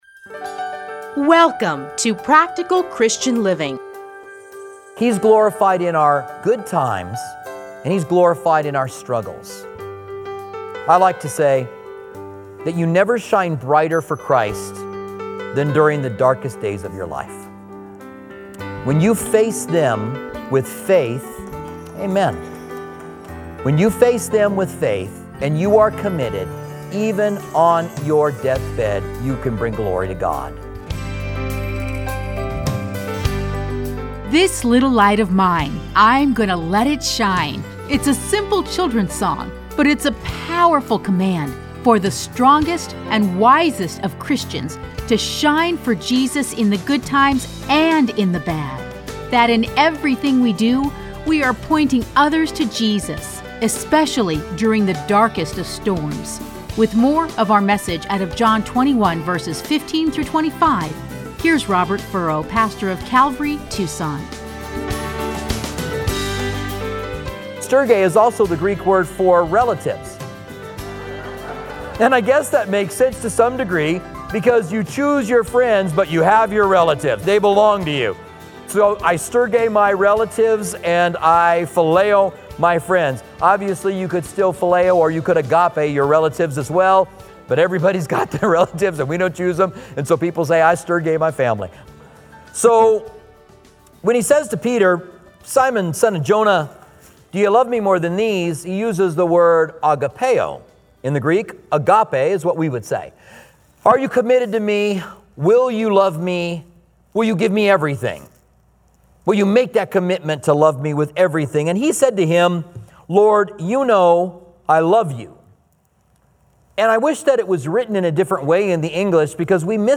Listen to a teaching from John 21:14-25.